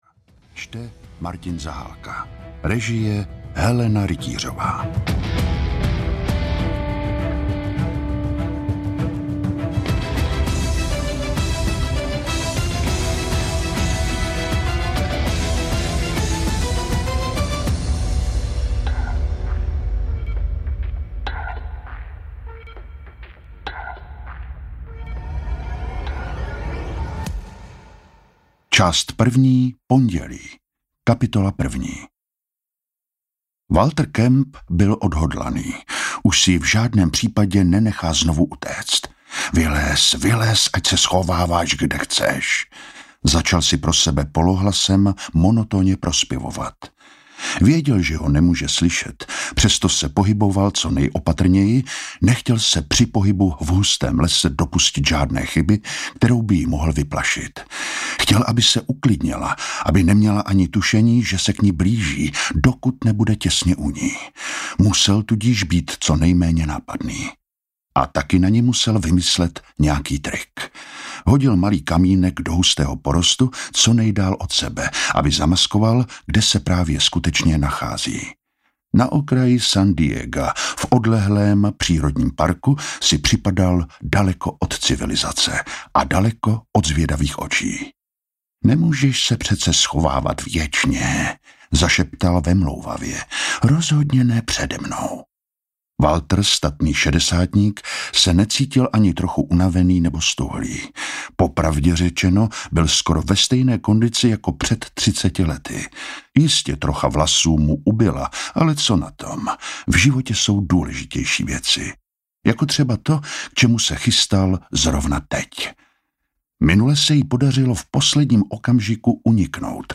Pavučina audiokniha
Ukázka z knihy